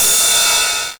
RIDE 1.wav